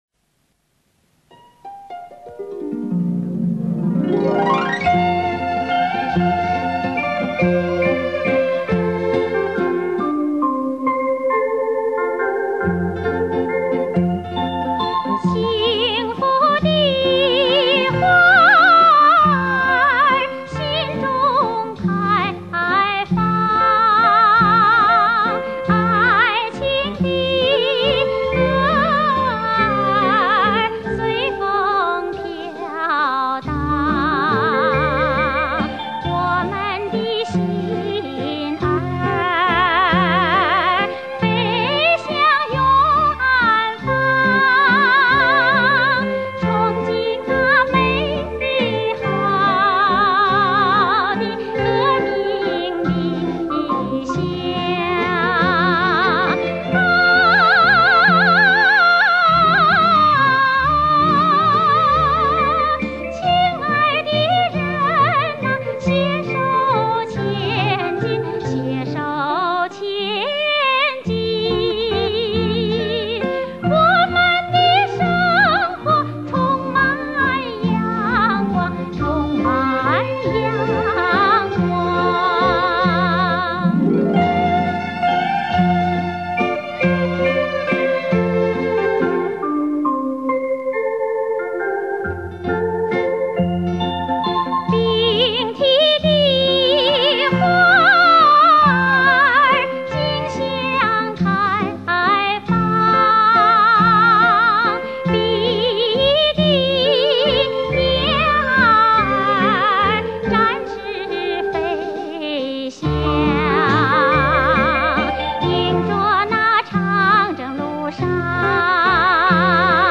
还有，就是这首歌曲当时好像比较轰动，是首次以电子琴伴奏的电影歌曲吧。